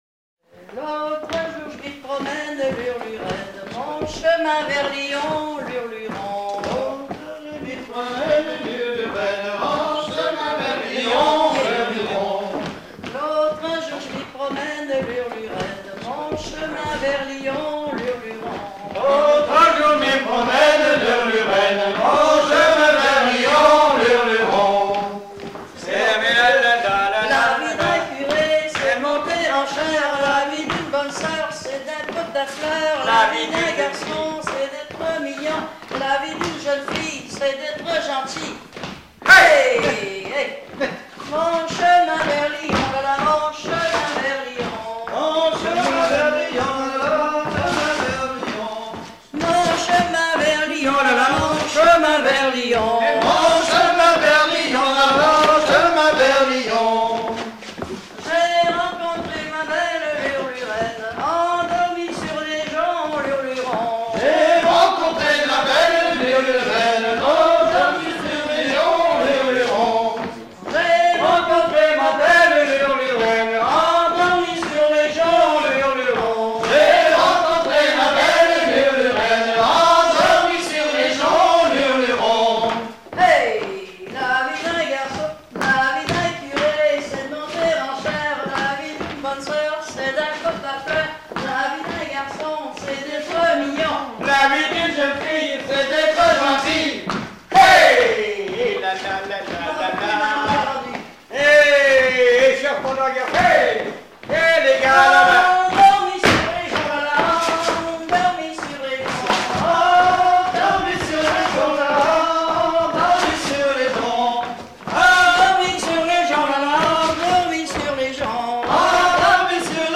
danse : ronde : grand'danse
Genre laisse
Pièce musicale éditée